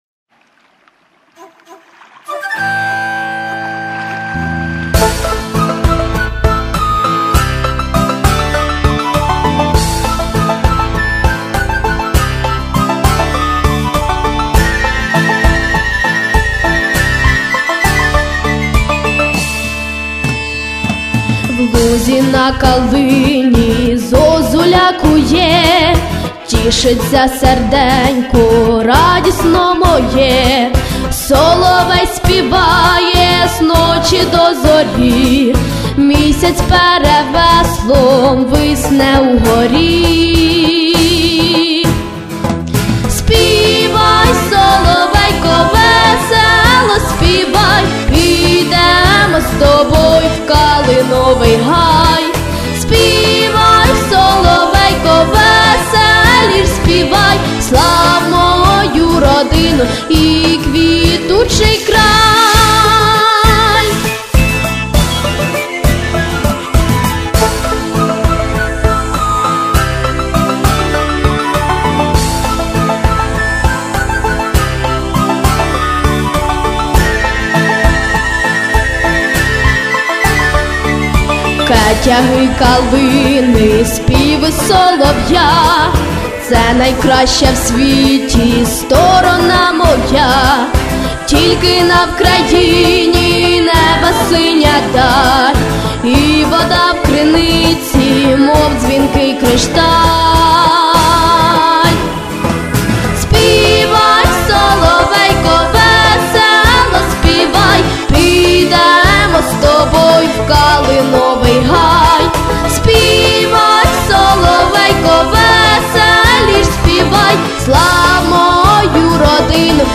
Рубрика: Поезія, Авторська пісня